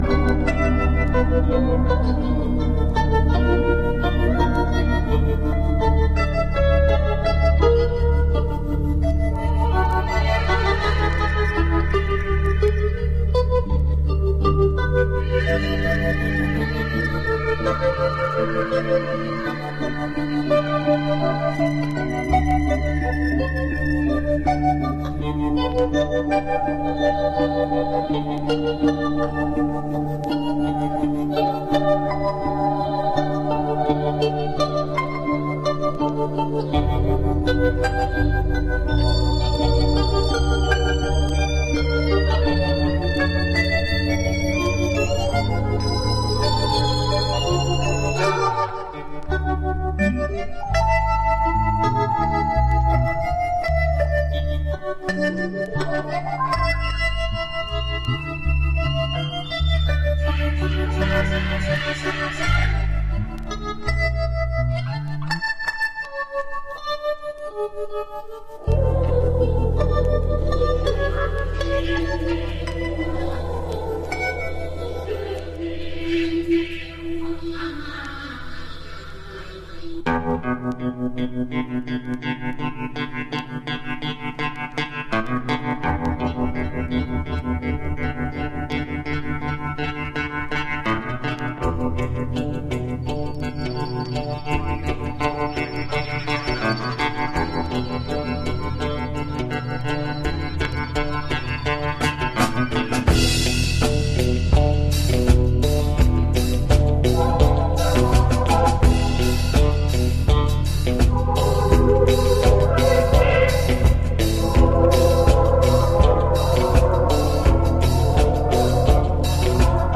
House / Techno
London Live 2009